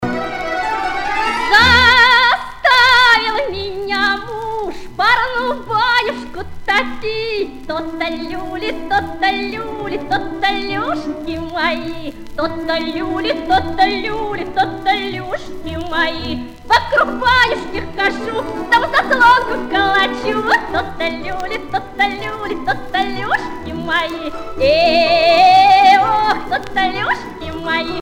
Russian folk songs
Pièce musicale éditée